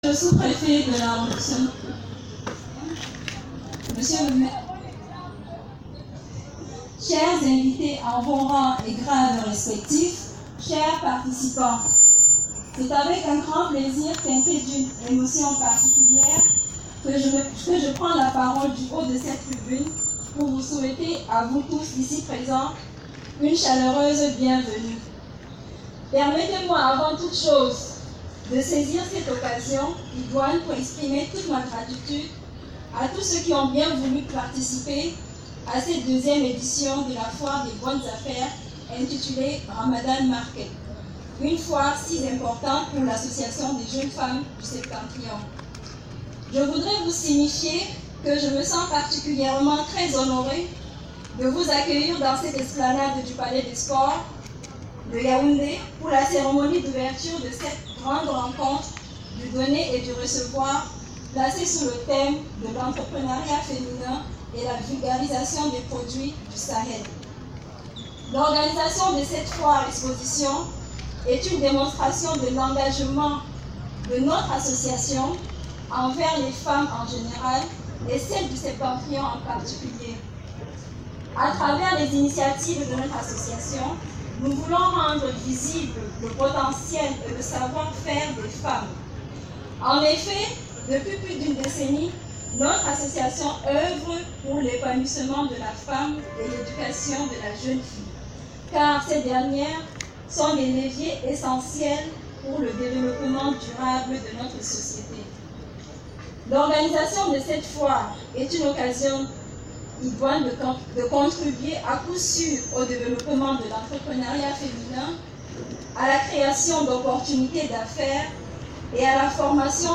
La cérémonie inaugurale, empreinte de solennité, s’est tenue en présence des autorités locales, symbolisant l’adhésion institutionnelle à cette initiative en faveur de l’entrepreneuriat féminin.
Mot du comité d’organisation de Ramadan Market